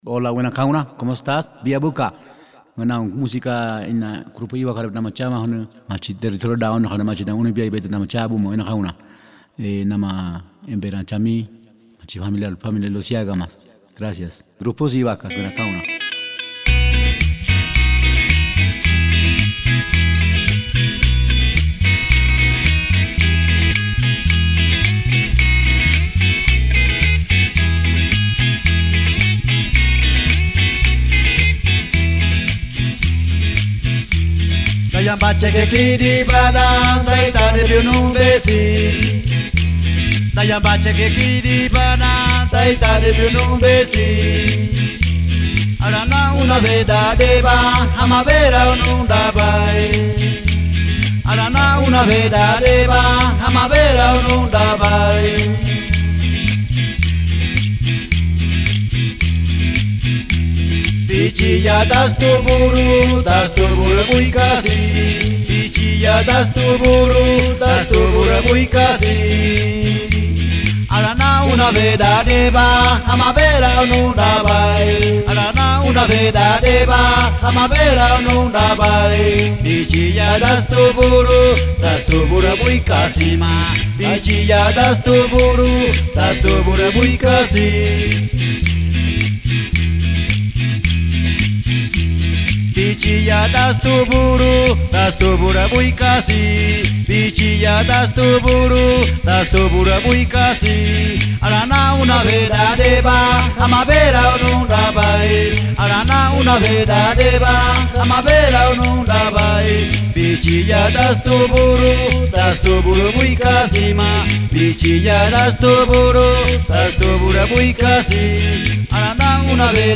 Canción
voz y guitarra.
Bajo y Puntero.
voz y percusió.